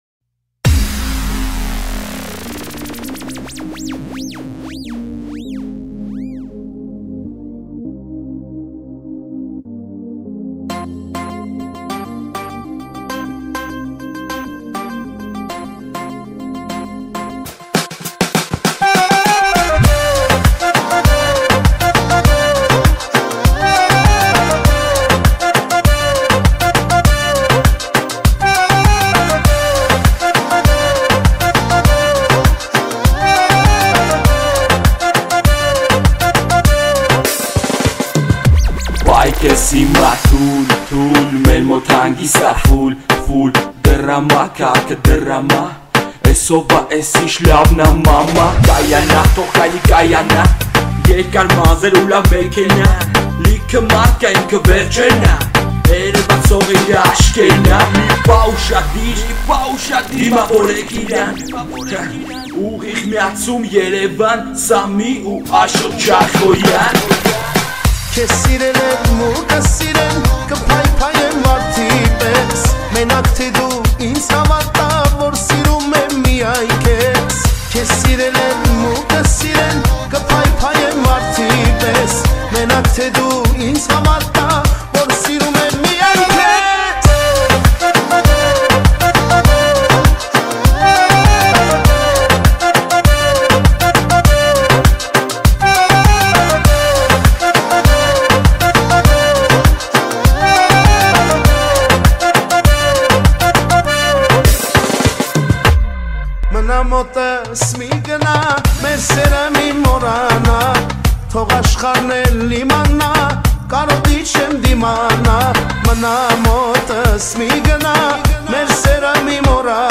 Erger 2017, Армянская музыка